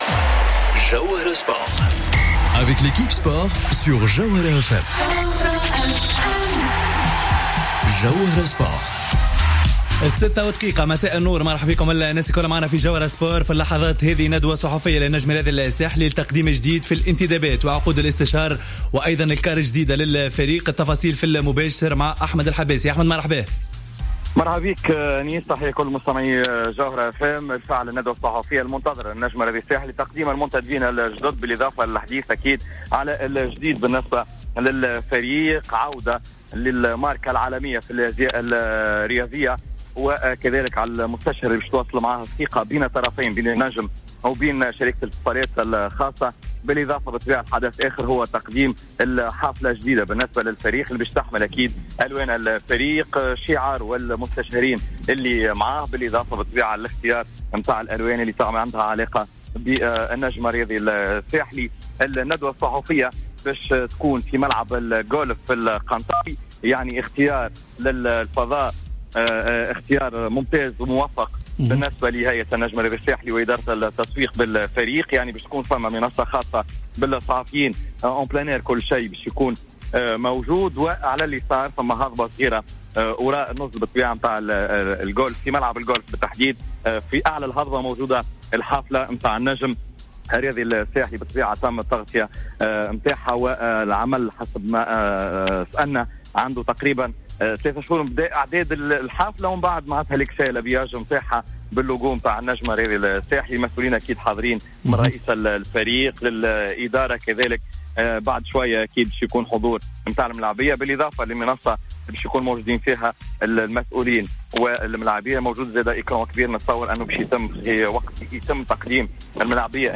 ندوة صحفية للنجم الساحلي